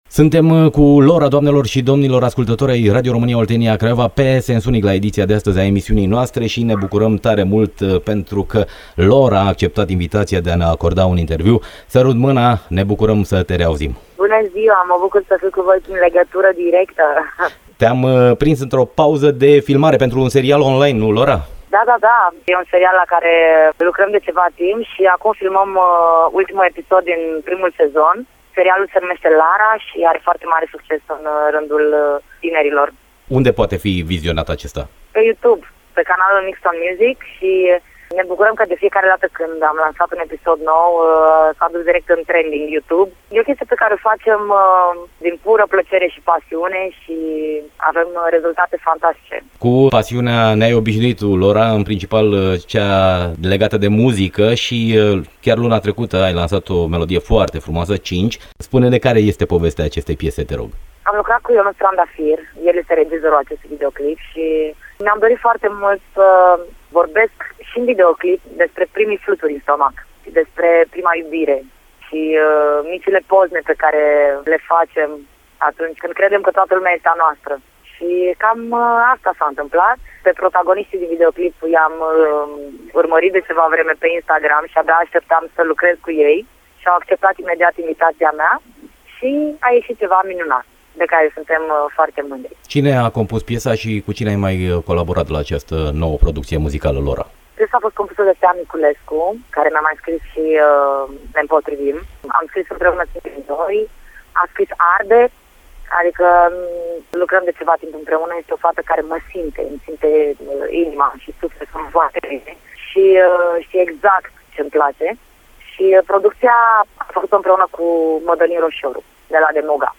Interviu cu Lora